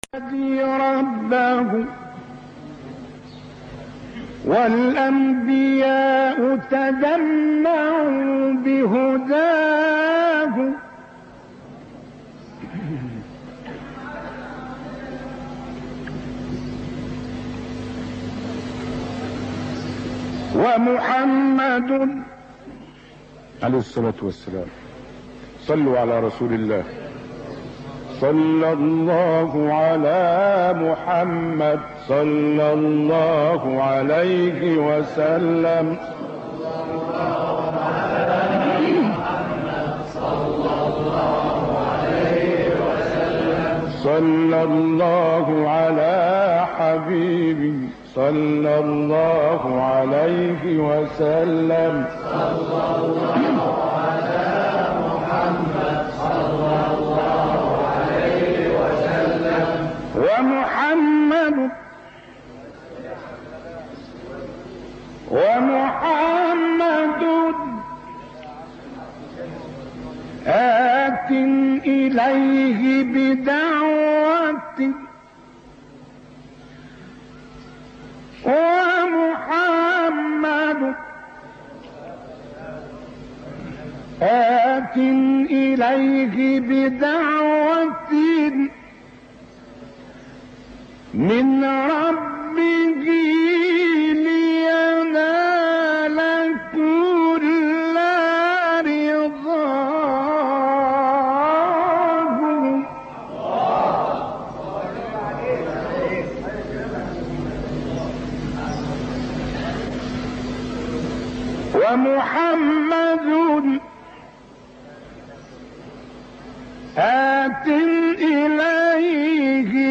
ابتهال زیبا